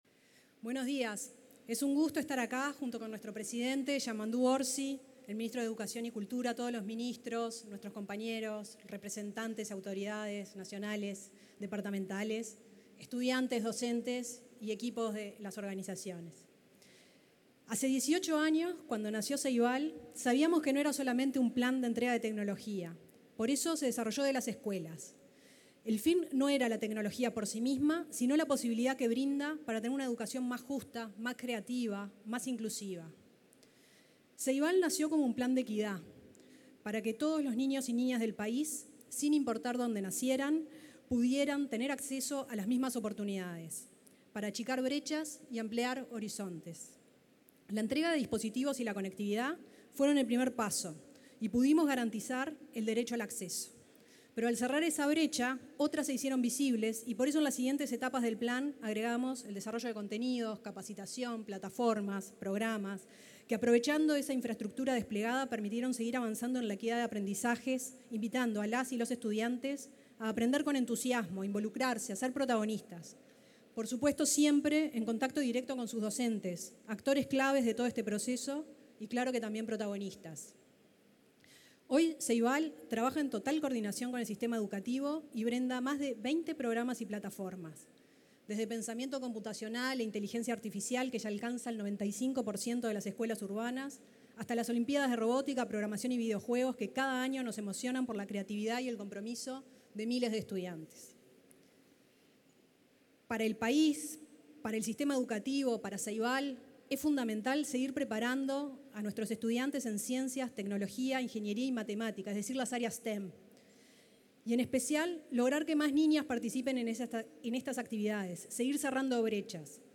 Palabras de los presidentes de Ceibal, Fiorella Haim, y ANEP, Pablo Caggiani
En el acto de presentación del primer centro educativo Tumo en Uruguay y la colocación de la piedra fundamental, se expresaron los titulares de Ceibal